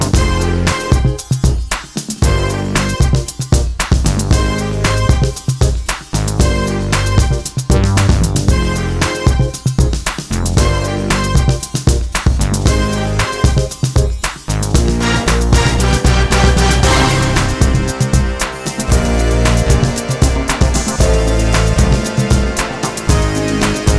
Instrumental Soundtracks 4:29 Buy £1.50